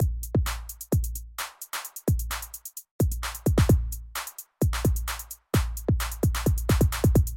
描述：快速的舞蹈鼓循环；这个循环很适合我的dminor合成器和dminor和弦。
Tag: 130 bpm Dance Loops Drum Loops 1.24 MB wav Key : Unknown